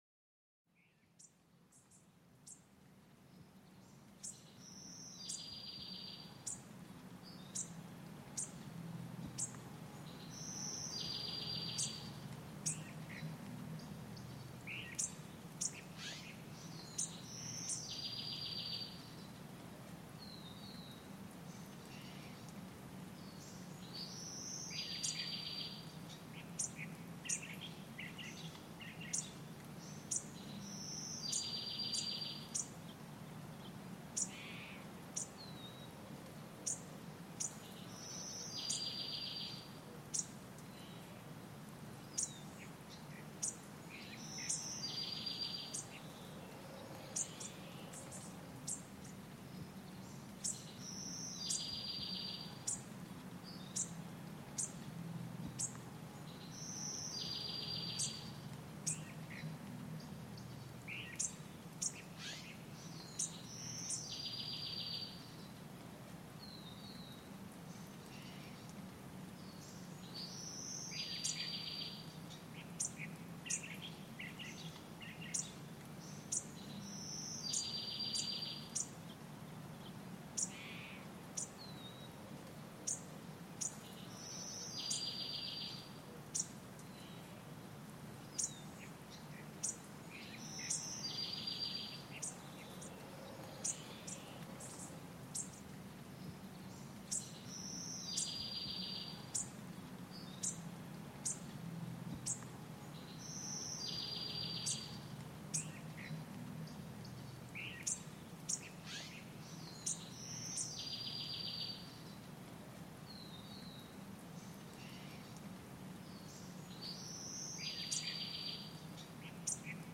Sonidos Relajantes de Pájaros para una Relajación Profunda